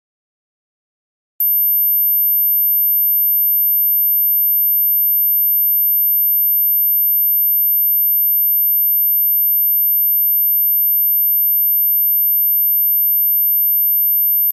49歳以下の方も聞こえる音。